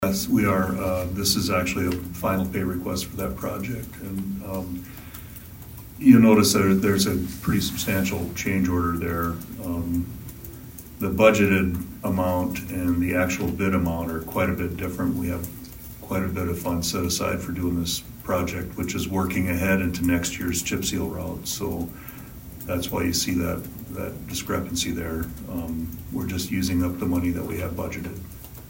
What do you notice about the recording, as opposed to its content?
ABERDEEN, S.D.(HubCityRadio)- At Monday’s Aberdeen City Council meeting, the council addressed two pay requests for projects currently in the works in Aberdeen.